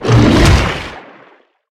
Sfx_creature_snowstalker_attack_swim_01.ogg